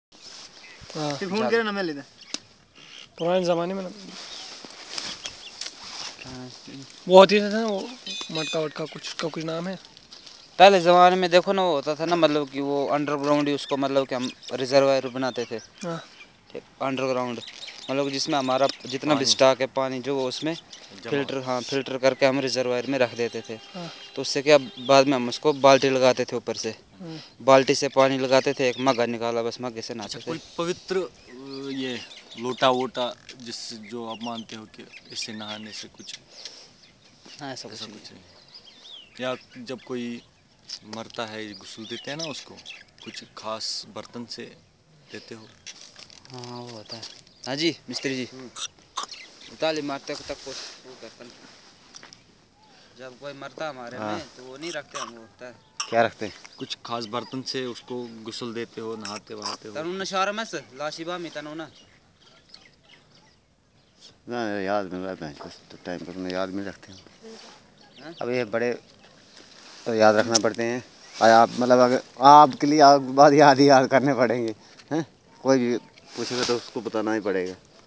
Conversation about the use of holy water